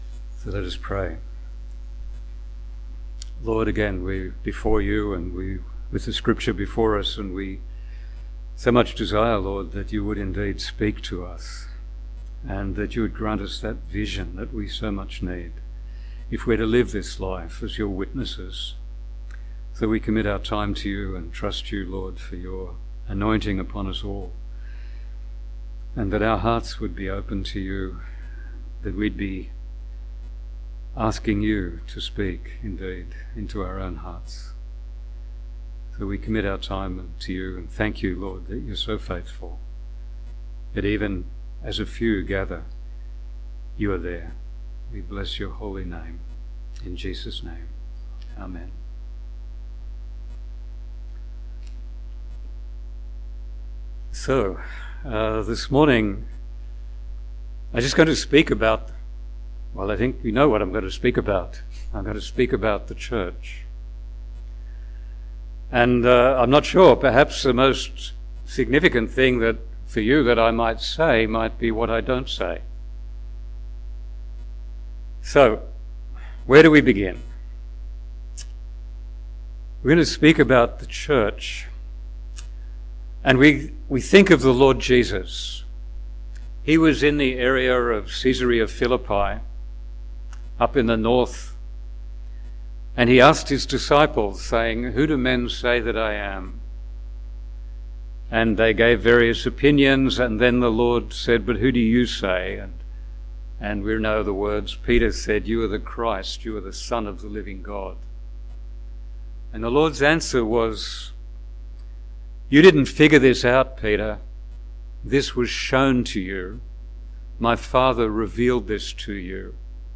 Sunday-Ministry-1-September-2024.mp3